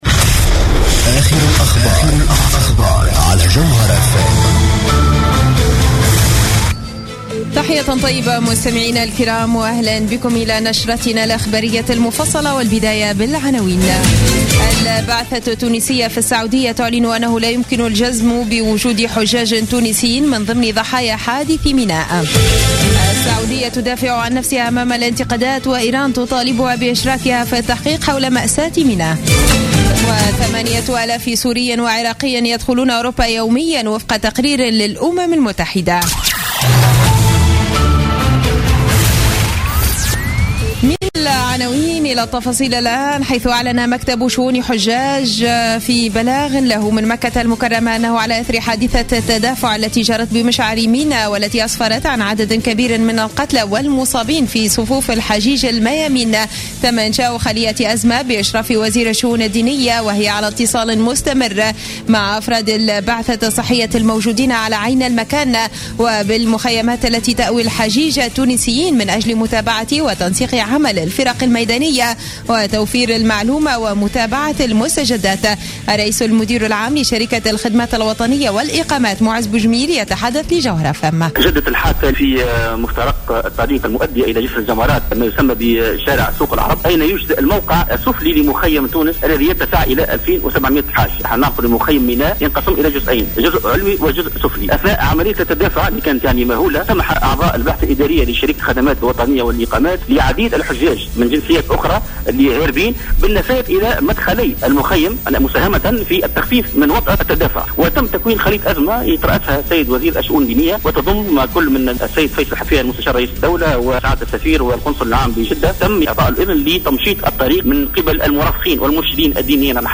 نشرة اخبار السابعة مساء ليوم الجمعة 25 سبتمبر 2015